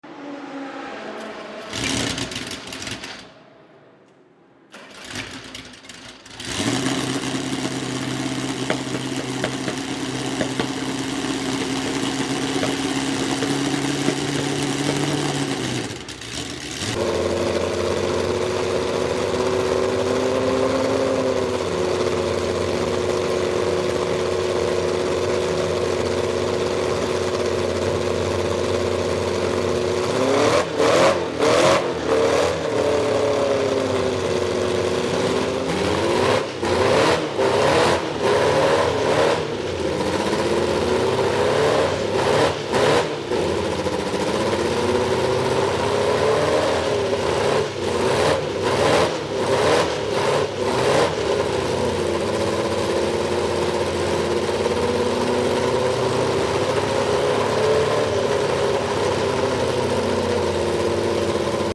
1966 BRM P83 F1 Car sound effects free download
1966 BRM P83 F1 Car Sound Warming Up Its 3.0L 16-Cylinder, H-Layout Engine!